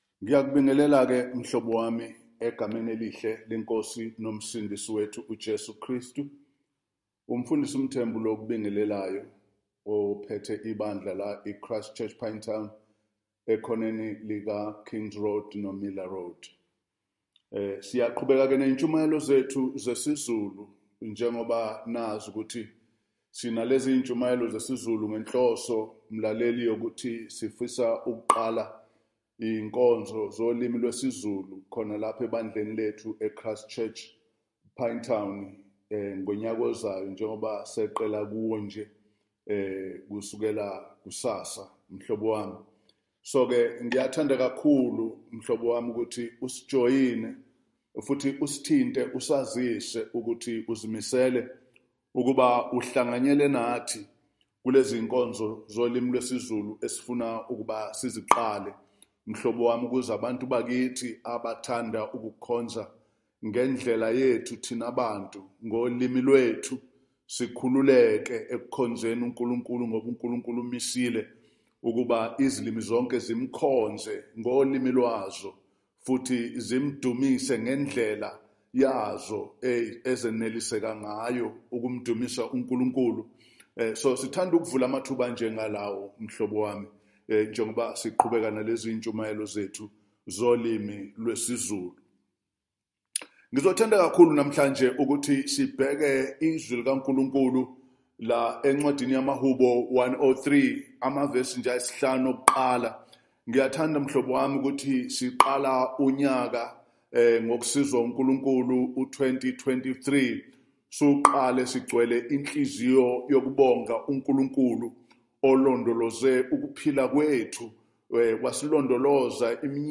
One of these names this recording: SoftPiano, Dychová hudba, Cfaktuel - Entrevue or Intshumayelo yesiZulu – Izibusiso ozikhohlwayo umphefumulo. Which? Intshumayelo yesiZulu – Izibusiso ozikhohlwayo umphefumulo